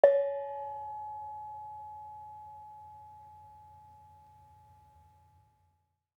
Gamelan Sound Bank
Bonang-C#4.wav